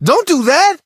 brock_hurt_02.ogg